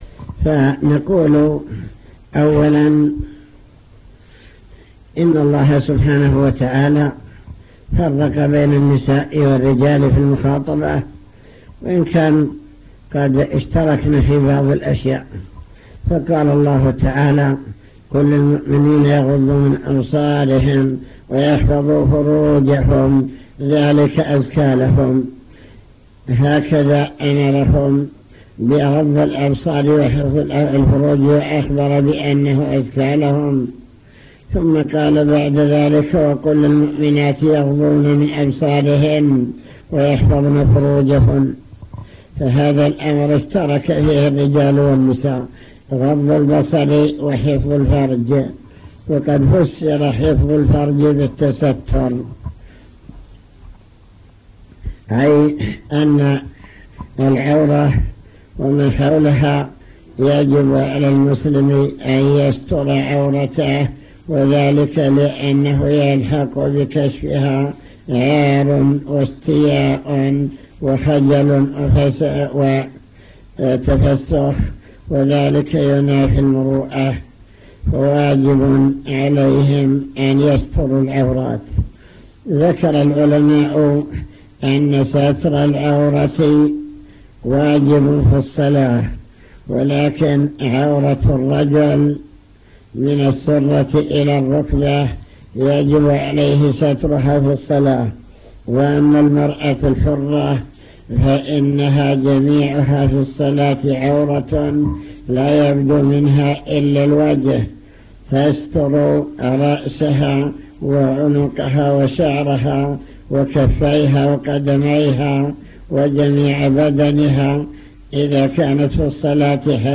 المكتبة الصوتية  تسجيلات - محاضرات ودروس  محاضرة بكلية البنات